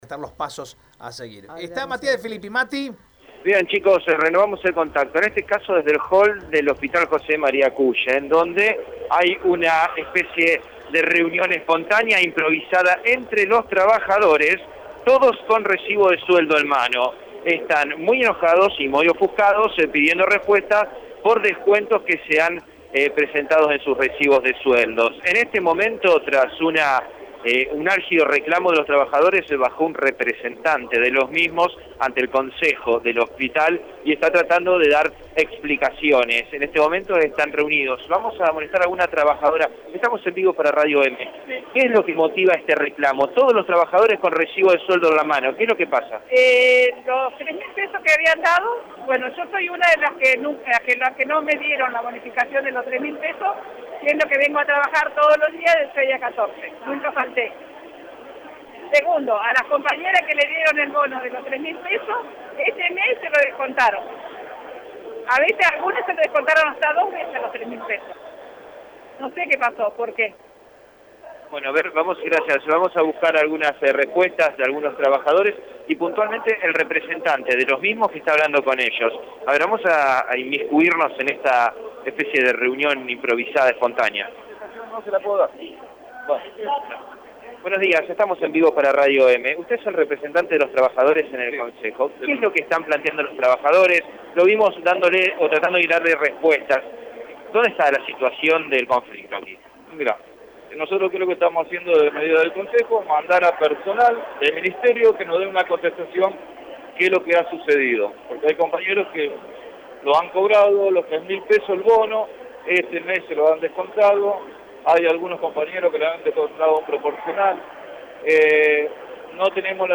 Trabajadores de salud del Hospital José María Cullen, se juntaron en el hall central,  con sus recibos de sueldos con el objetivo de reclamar irregularidades en su salario.
En dialogo con el movil de Radio EME, los trabajadores del nosocomio de la capital provincial expresaron con disgusto: Los tres meses que habían dado yo soy una de las que nunca me dieron la bonificación de los tres mil pesos, siendo que vengo a trabajar todos los días de 6 a 14, nunca falte, segundo a las compañeras que le dieron el bono este mes se los descontaron, a otros se lo descontaron hasta dos veces».